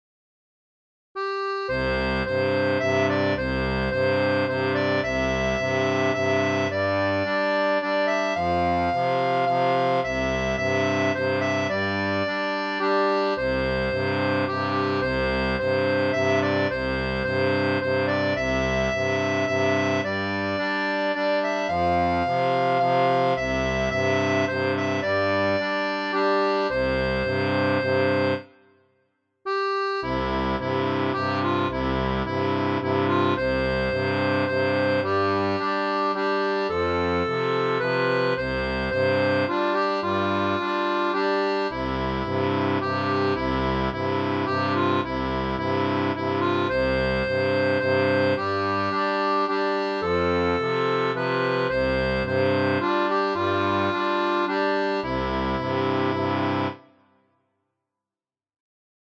Tablature incluant une voix piano ainsi qu’une voix flûte
Musique traditionnelle